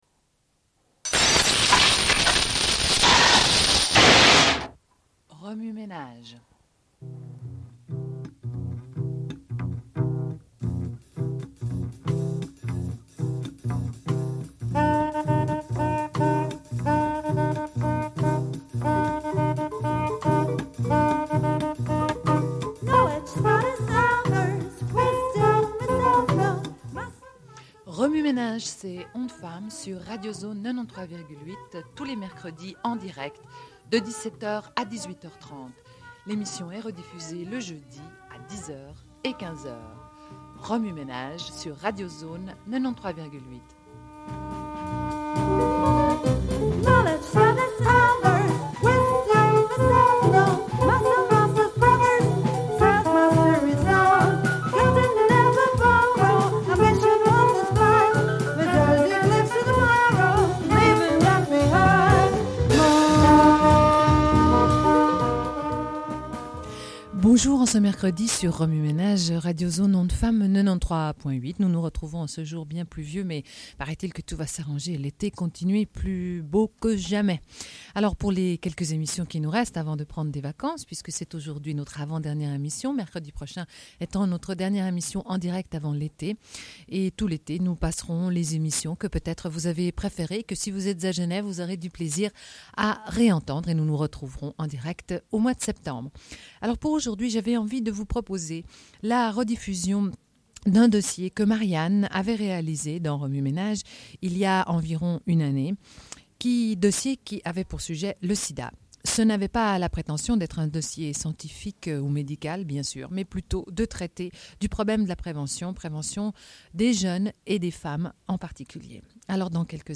Une cassette audio, face A00:31:02